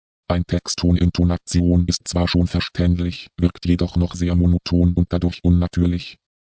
Ein Text ohne Intonation ist zwar schon verständlich, wirkt jedoch noch sehr monoton und dadurch unnatürlich (